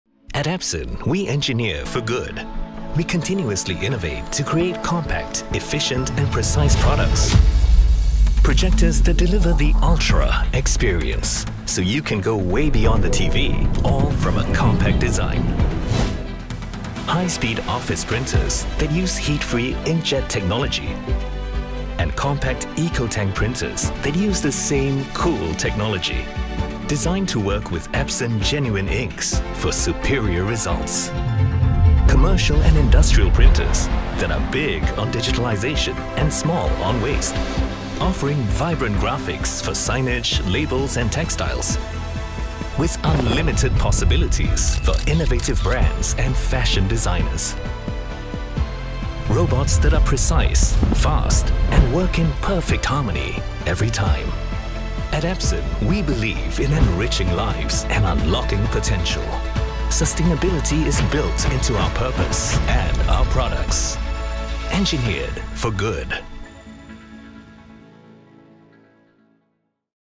Englisch (Singapur)
Warm
Autorisierend
Konversation